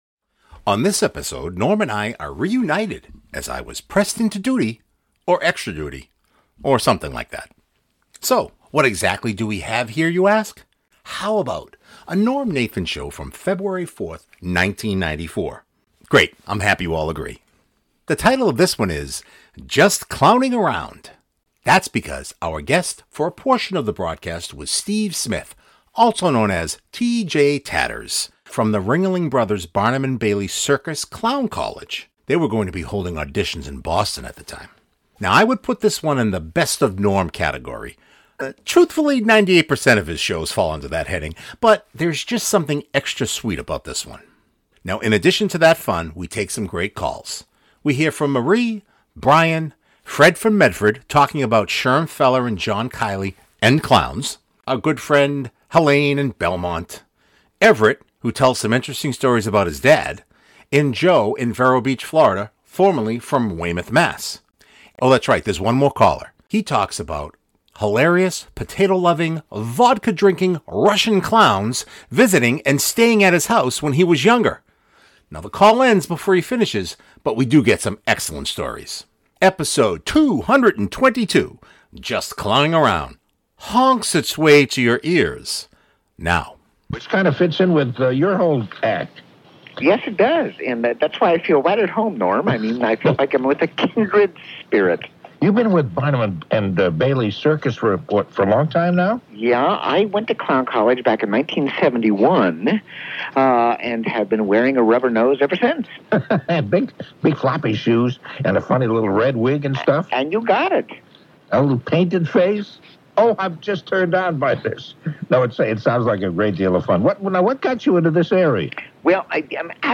How about a NNS from February 4th, 1994?